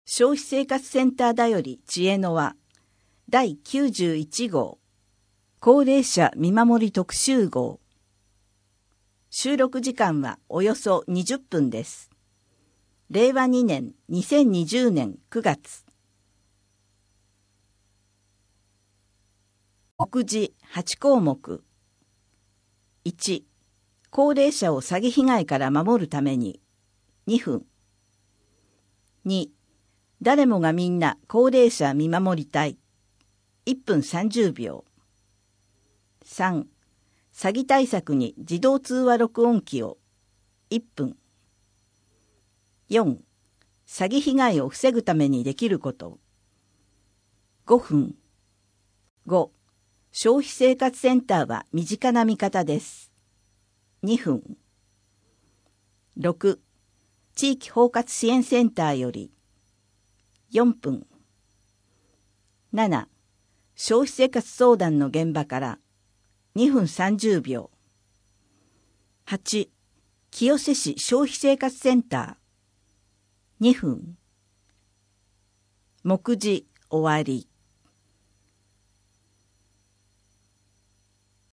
地域包括支援センターより 4面 消費生活相談の現場から 声の広報 声の広報は清瀬市公共刊行物音訳機関が制作しています。